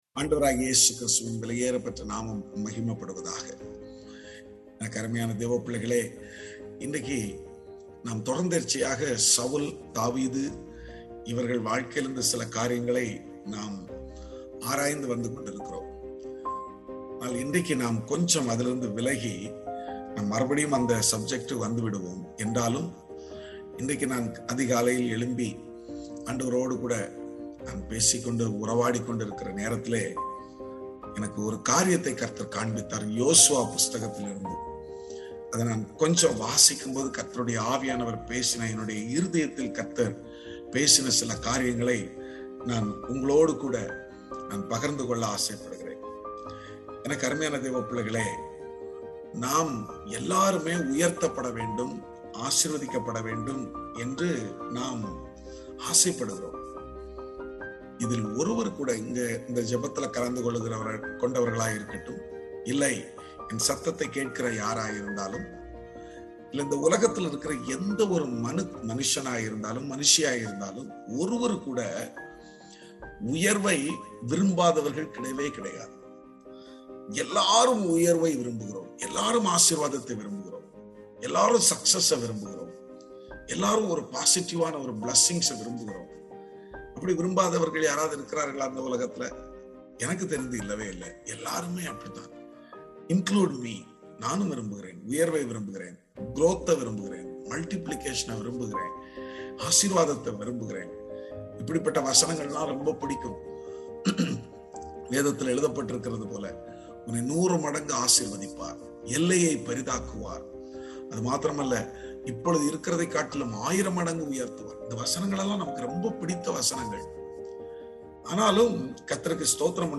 Morning Devotion